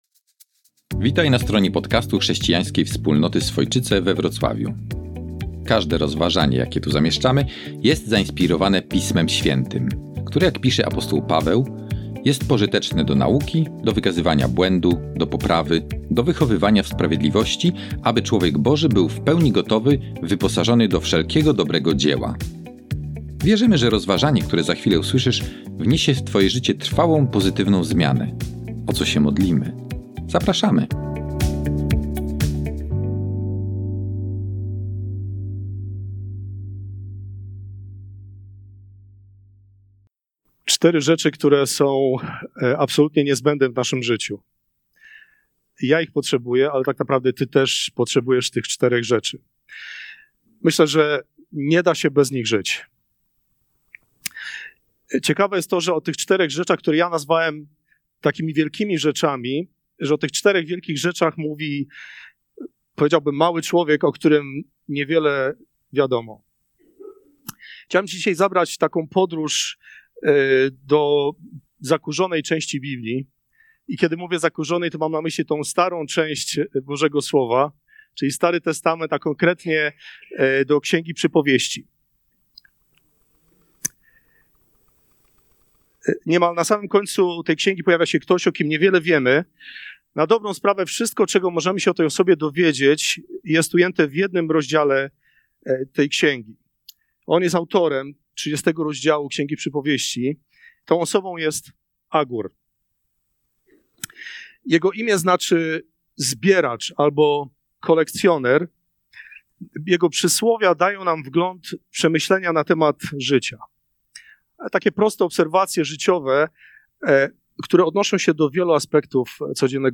Nauczanie z dnia 5 stycznia 2025